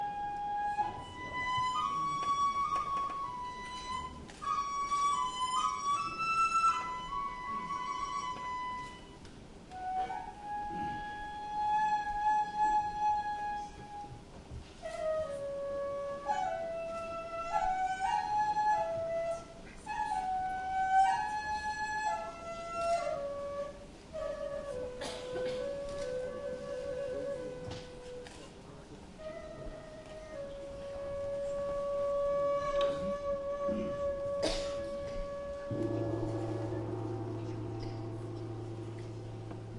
中国 " 冥想拍手小组稳定的节奏 天坛，中国北京
描述：冥想拍手组稳定节奏天坛北京，China.flac
标签： 冥想 中国 稳定 拍手 北京 集团 天坛 节奏
声道立体声